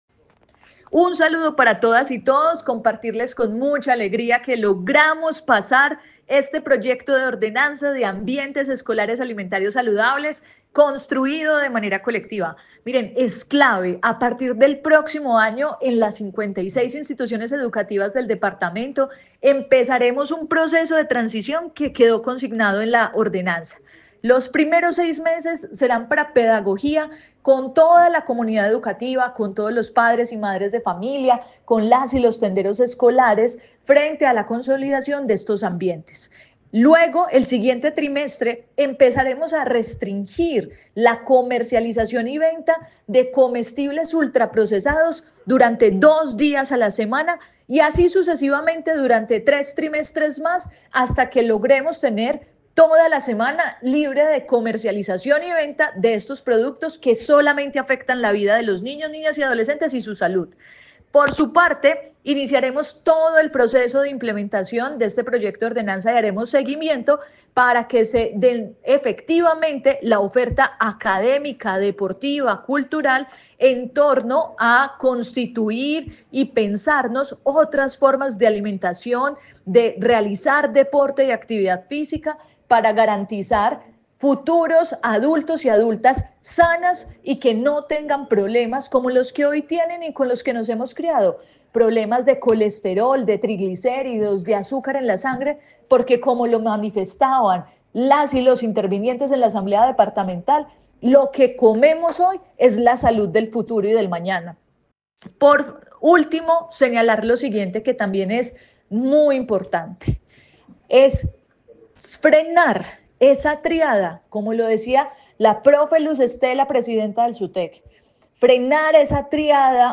Jessica Obando diputada del Quindío